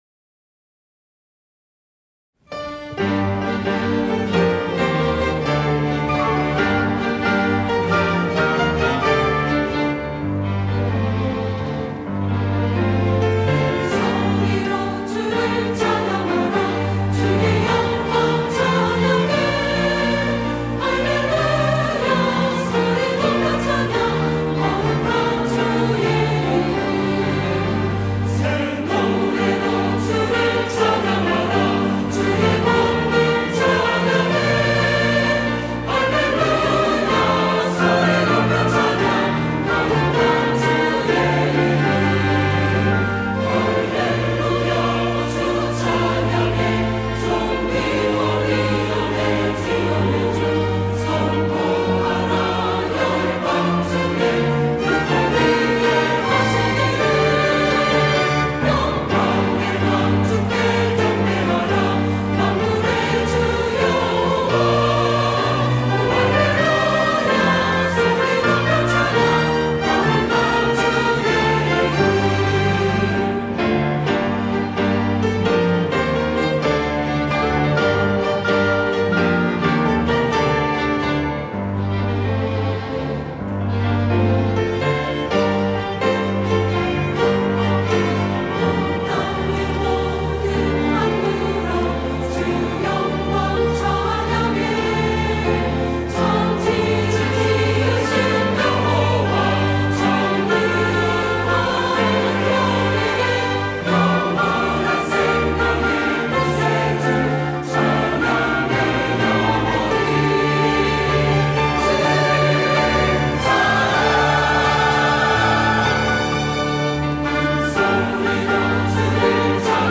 호산나(주일3부) - 할렐루야 주 찬양
찬양대